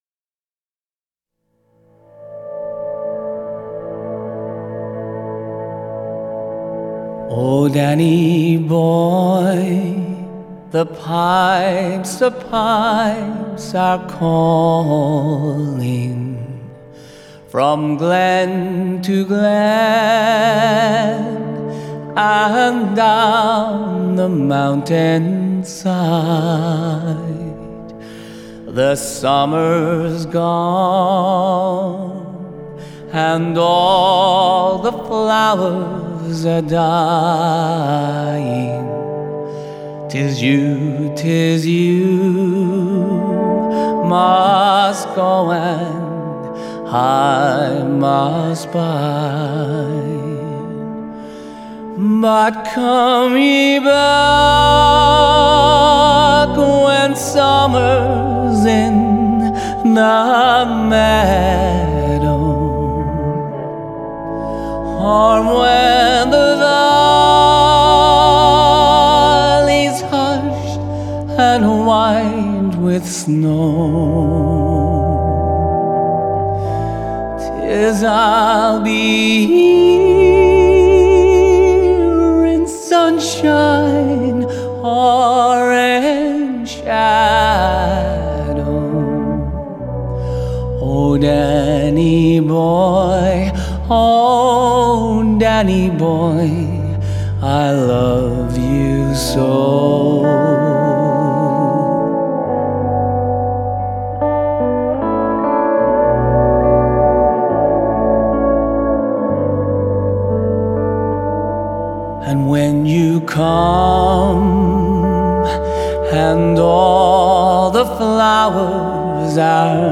Genre: Folk/Country/Pop